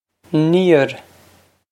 níor neer
This is an approximate phonetic pronunciation of the phrase.